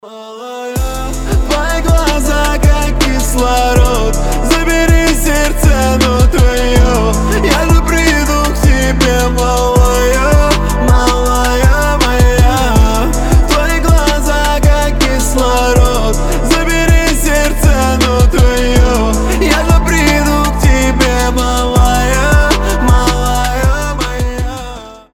• Качество: 320, Stereo
мужской вокал
лирика